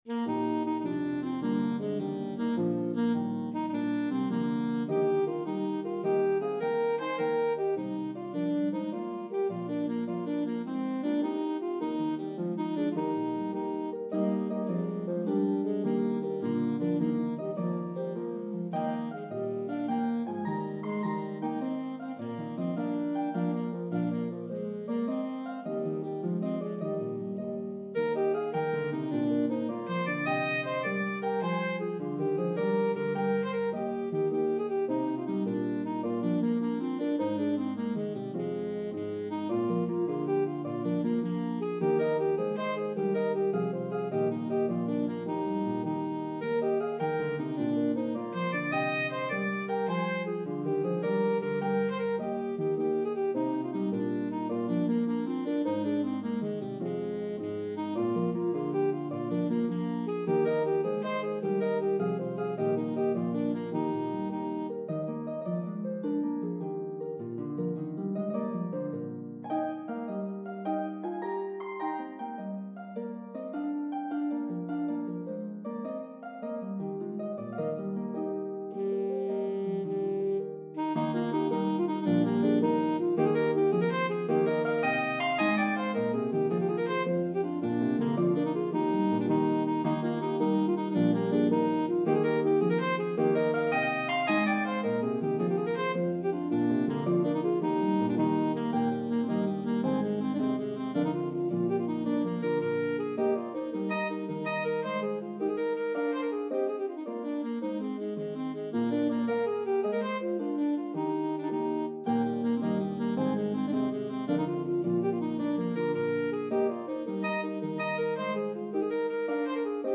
The medley progresses through 3 keys.
The Harp part is playable on either Lever or Pedal Harps.